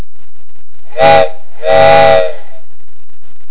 trainStereo8.wav